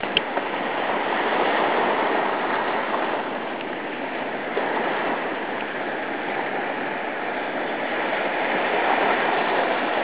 Sunrise over Kaaawa, Hawaii
Be sure not to miss the sounds of the surf (below).
To hear the sound of the ocean, click here
surf.aiff